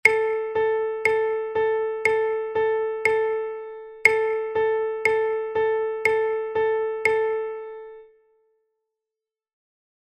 Gerade Achteln:
gerade8eln.mp3